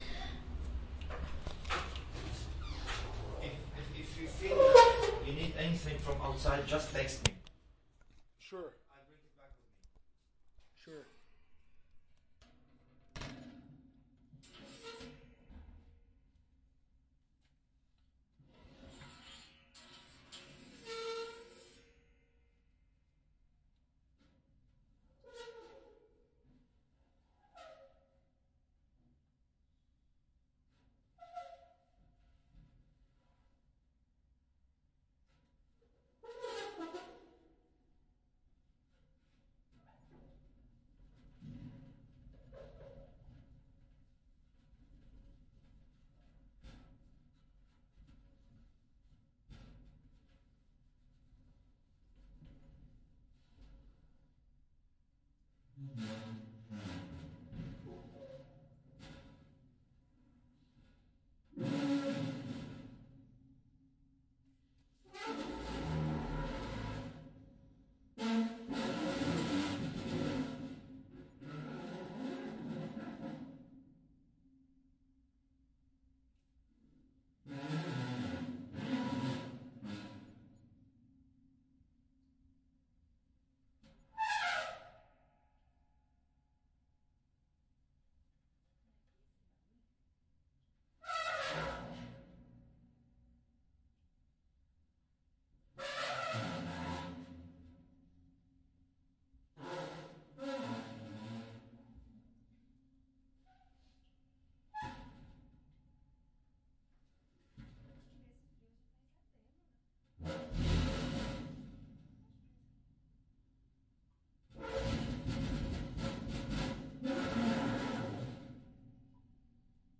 描述：这是在我的厨房里，用手指在炉子的灶台上摩擦，用Zoom便携式录音机录制的。这个文件是未经任何编辑的原始录音。
Tag: 厨房 声学 共振 声音效果 未编辑 炉灶 噪声